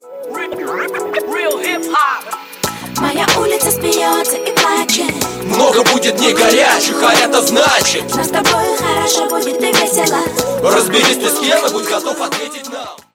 рэп музыка